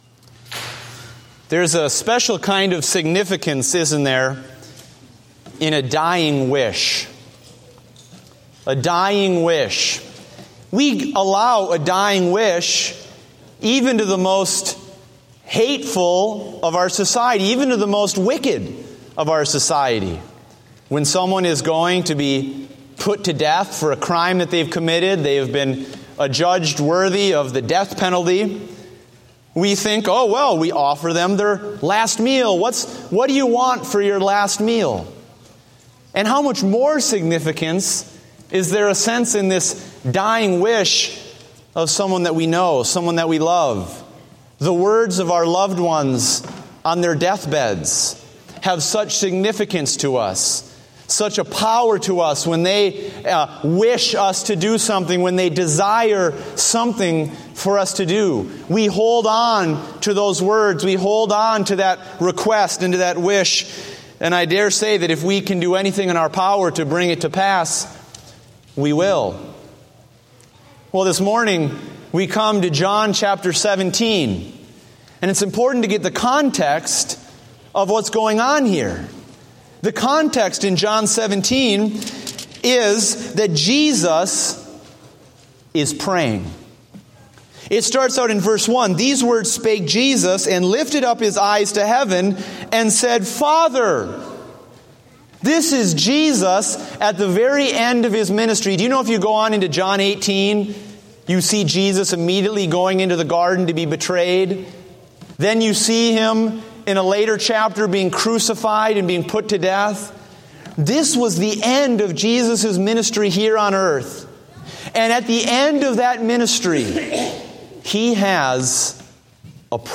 Date: June 14, 2015 (Morning Service)